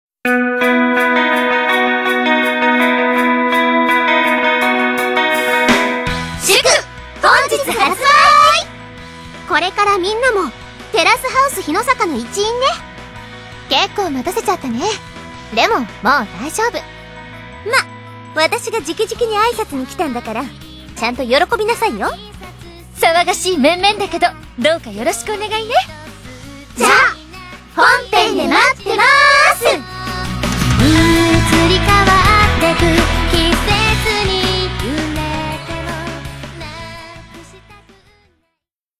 発売記念ボイス配信中！
「世界でいちばんな発売記念のボイスメッセージ」公開！